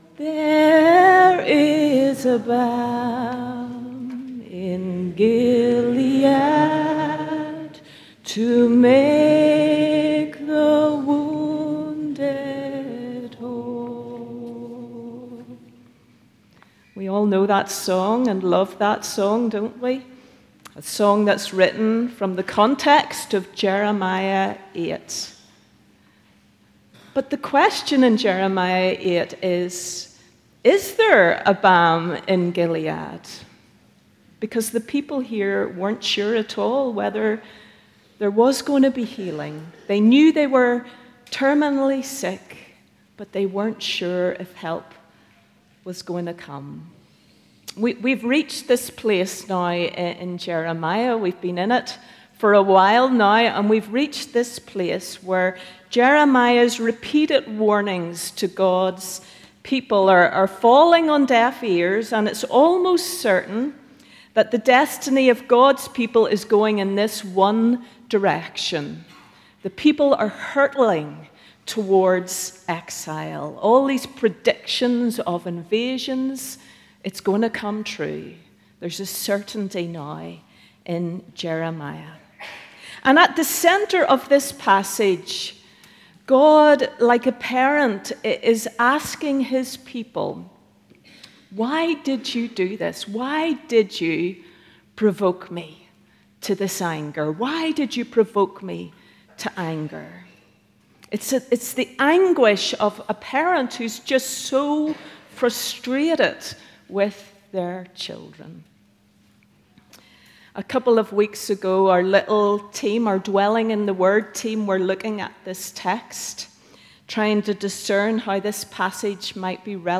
Church of the Servant Sermons Is There a Sickness Beyond Healing?